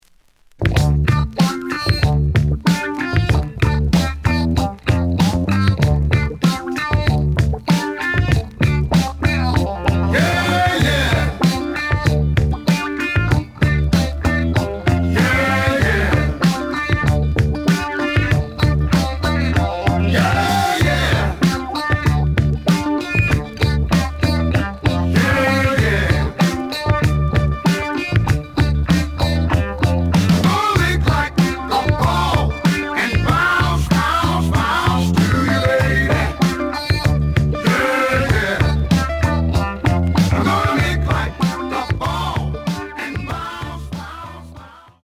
試聴は実際のレコードから録音しています。
●Genre: Funk, 70's Funk
●Record Grading: VG+ (盤に若干の歪み。多少の傷はあるが、おおむね良好。)